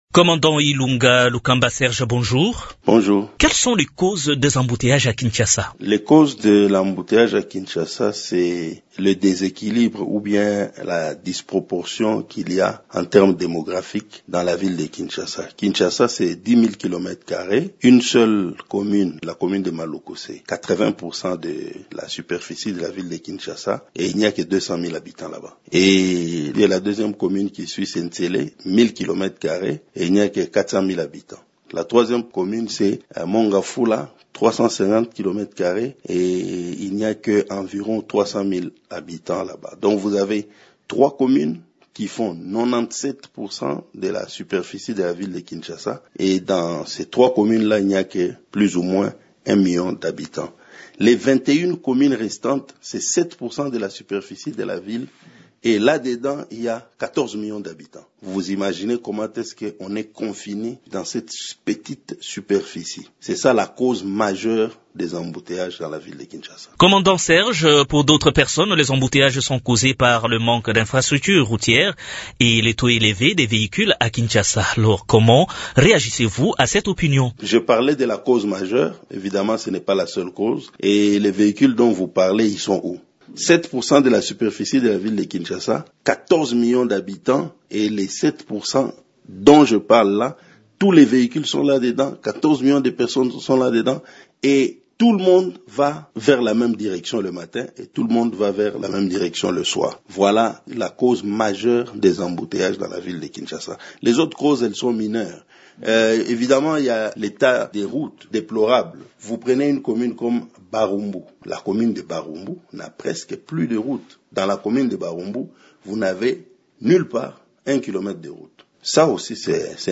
Dans un entretien accordé à Radio Okapi